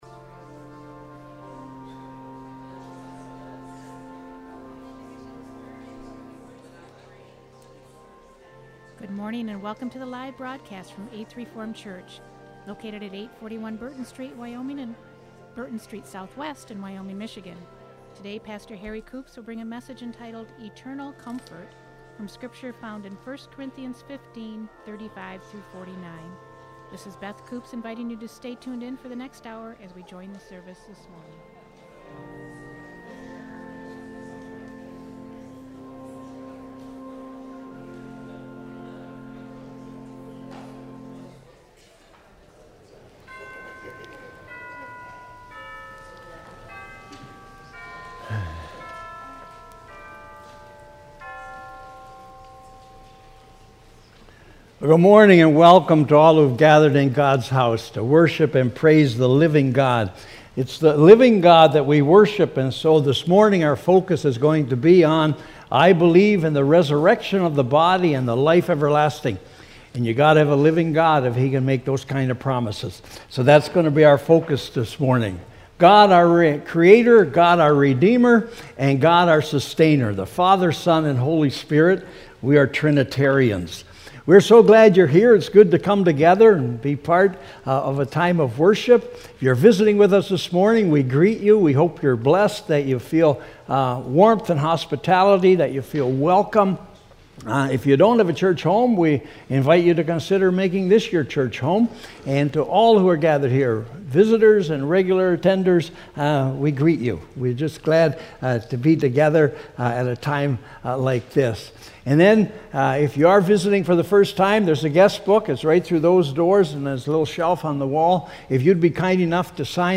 Worship Services | Eighth Reformed Church
Current Sermon